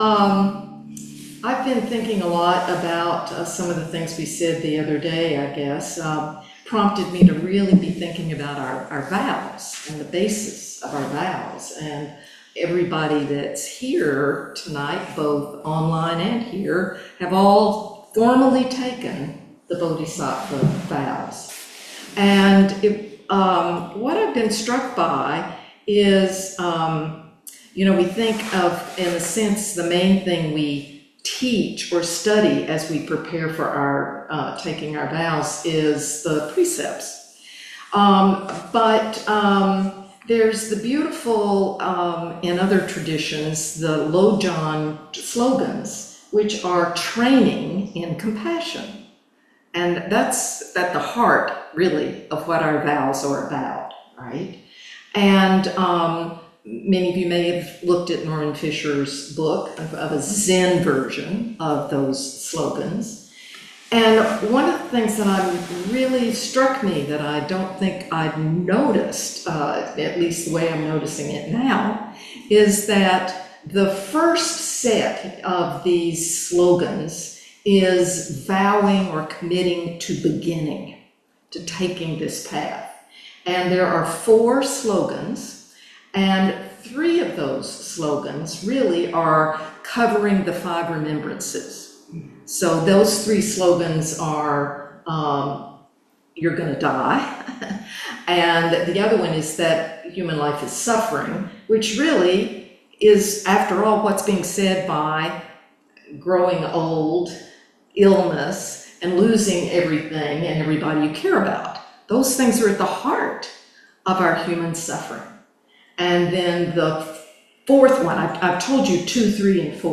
In-Person Evening Zazen with Zoom option at All Saints by-the-Sea Anglican Church, Upper Hall, 110 Park Drive, Ganges
Dharma Talk